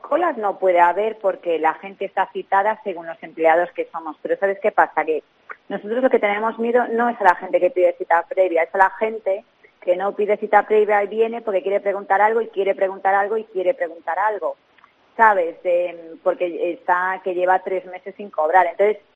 Funcionaria del SEPE